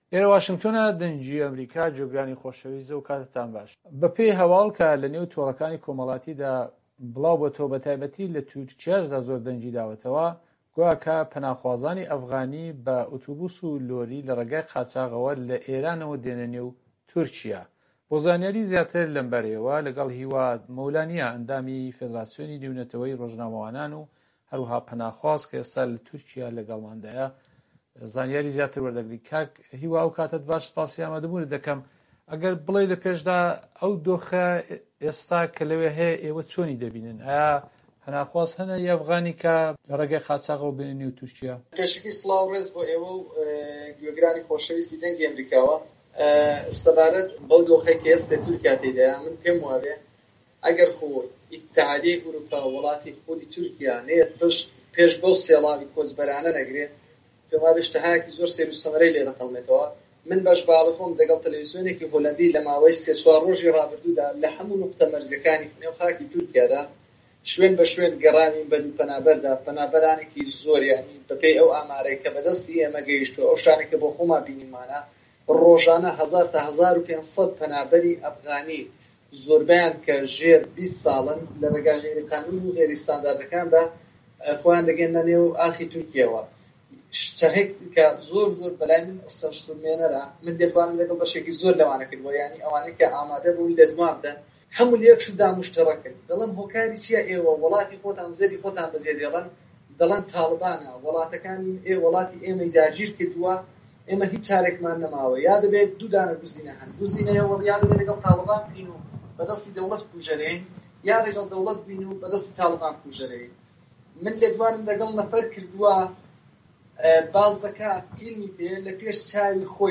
وتووێژەکان